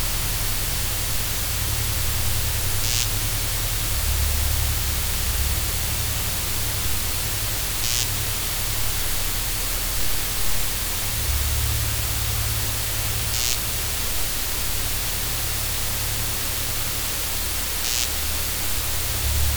Trying to identify an intermittent noise caught by instruments pickups
It's not a 60hz hum.
I attached a file - the volume is massively boosted, but it's pretty easily audible at normal volumes.
This is my bass with single coil pickups, both at full volume. If I turn down either of the pickups, I get the characteristic background hum of single coils, but the intermittent noise still comes through.